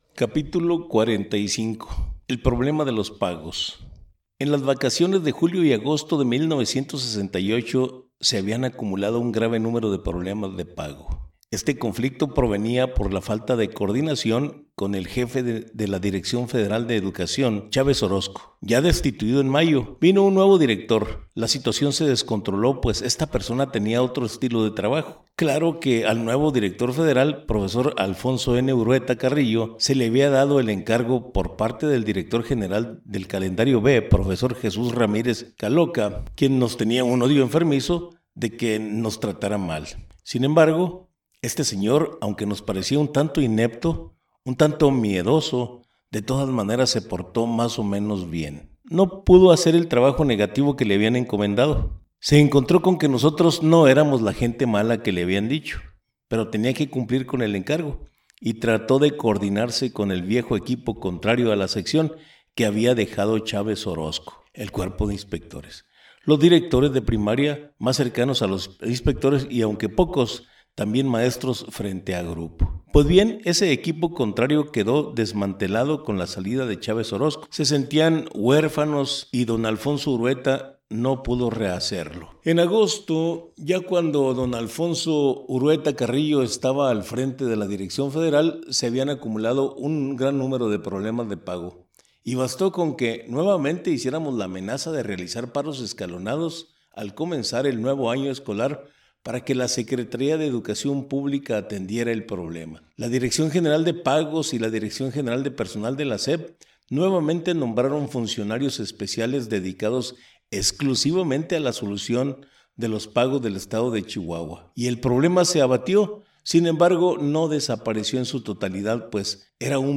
AUDIOLIBRO